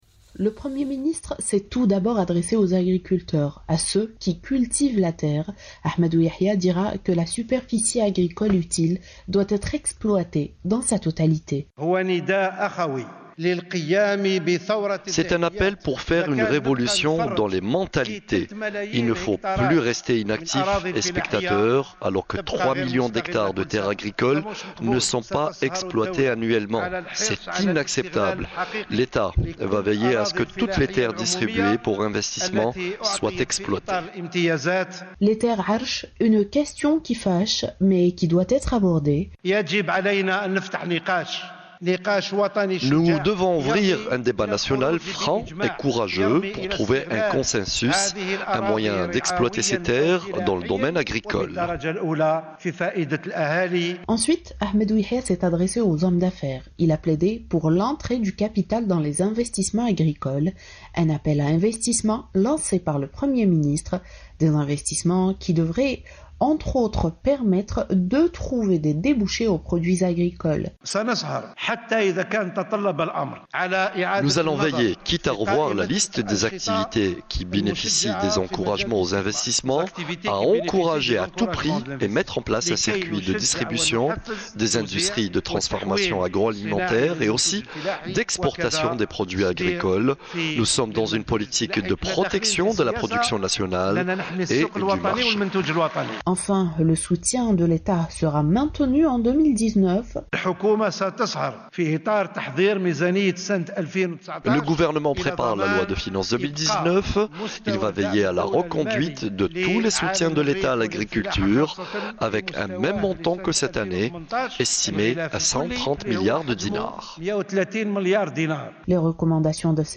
Accès au crédit: quelles difficultés pour les jeunes agriculteurs. Le reportage